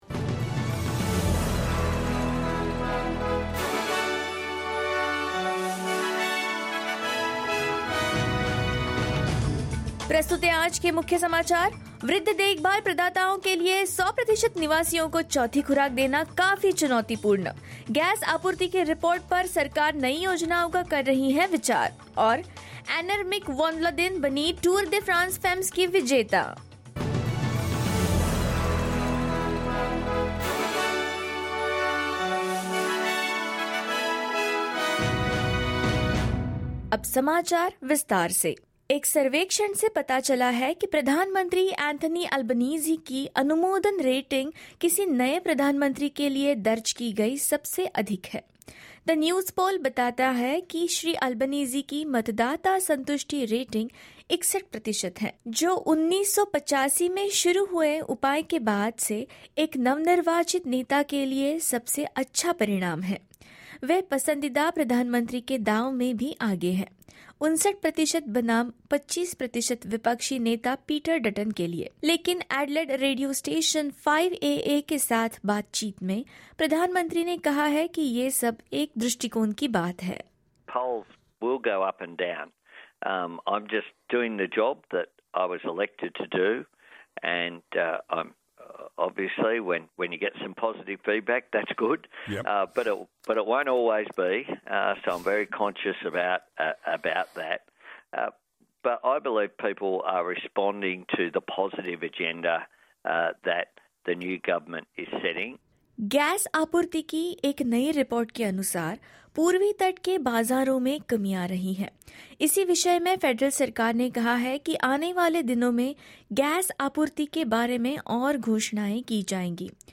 hindi_news_0108.mp3